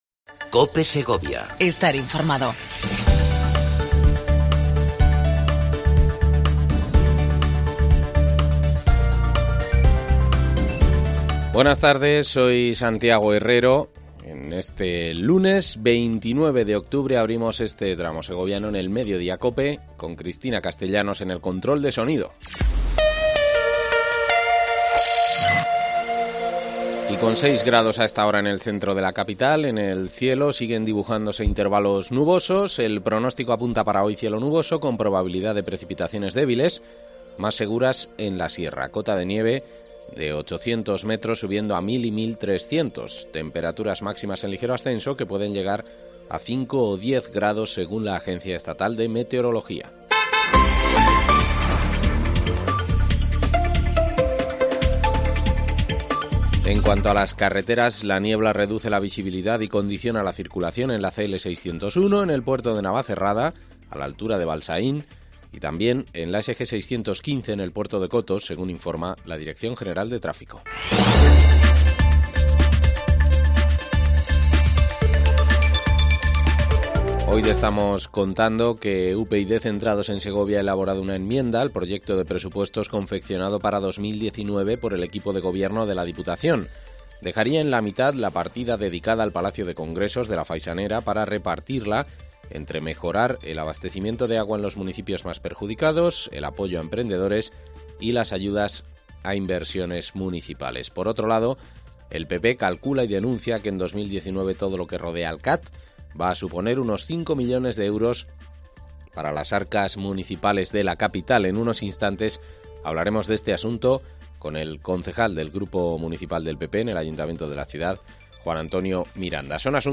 AUDIO: Entrevista a Juan Antonio Miranda, concejal del PP en el ayuntamiento de Segovia